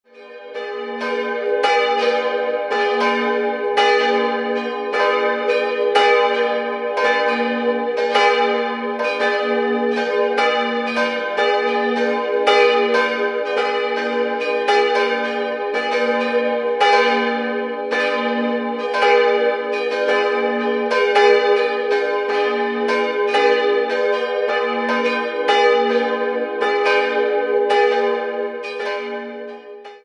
Die Dorfkirche spätgotischen Ursprungs und dem charakteristischen Treppengiebelturm wurde im Jahr 1728 barockisiert. 3-stimmiges As-Dur-Geläute: as'-c''-es'' Die beiden größeren Glocken wurden 1922 beim Bochumer Verein gegossen, die kleinere stammt aus dem Jahr 1785 von Matthias Stapf in Eichstätt.